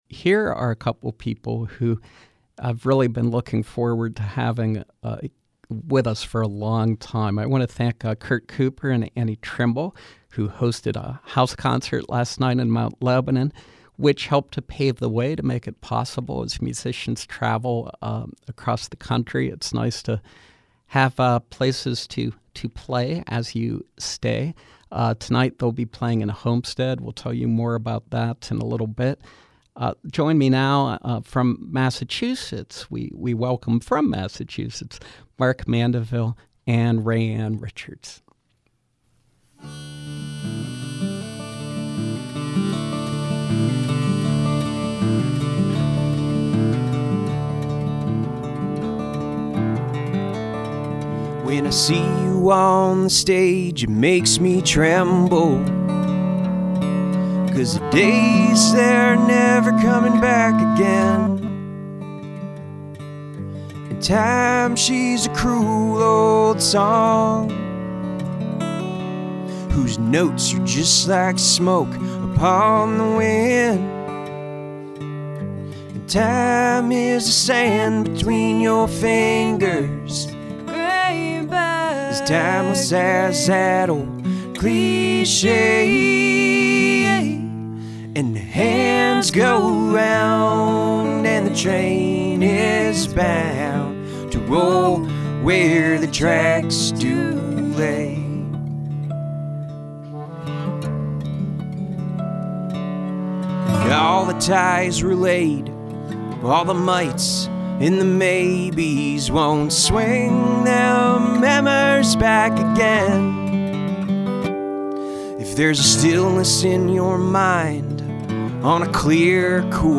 multi-instrumentalists